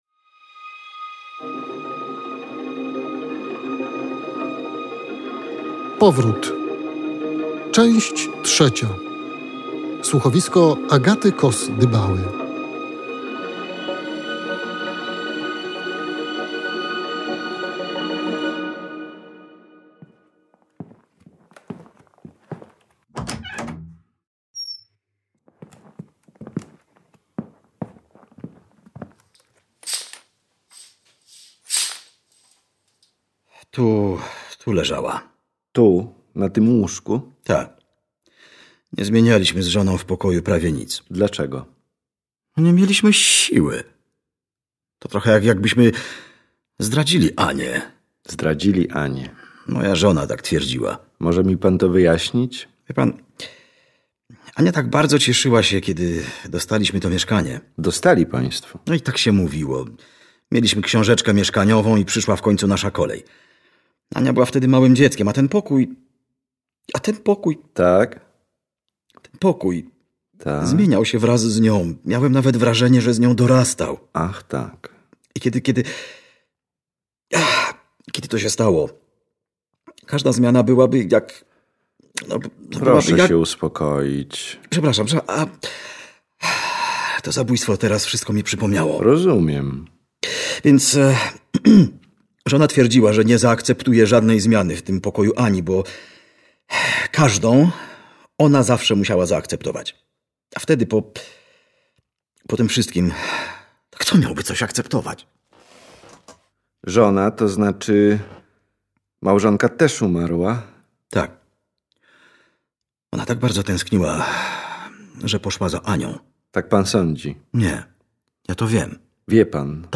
Ten, o którym opowiada słuchowisko „Powrót” powoduje tragiczne konsekwencje. O nich więcej w trzecie części tej kryminalnej opowieści.